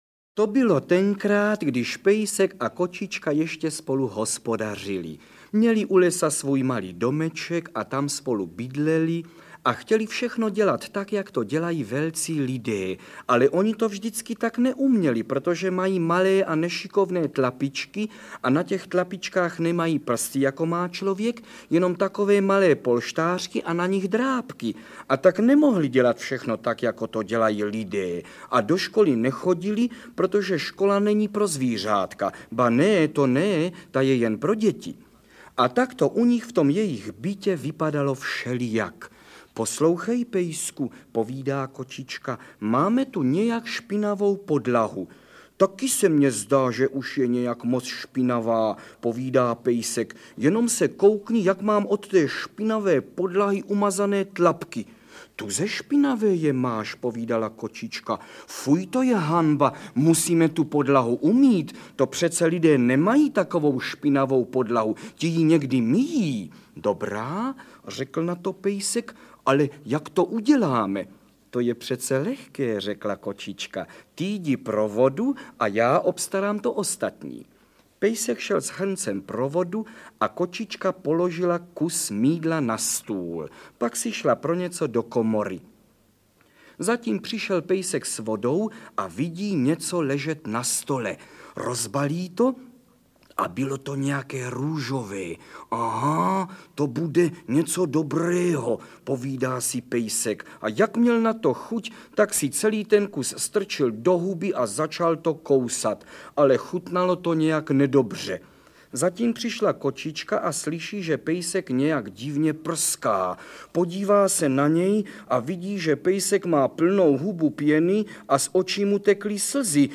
Interpret:  Karel Höger
Příběhy pejska a kočičky od Josefa Čapka (*23. března 1887 – †duben 1945) patří k základním dílům české literatury pro děti. Karel Höger čte toto líbezné vyprávění s humorem, něhou a citem na zvukové nahrávce, neustále pro velký zájem posluchačů reedované.